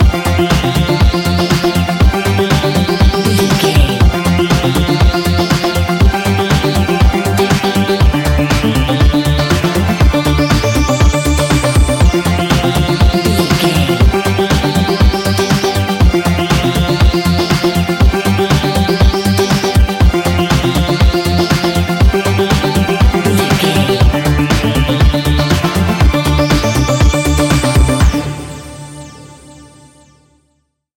Ionian/Major
D
house
electro dance
synths
techno
trance